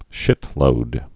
(shĭtlōd)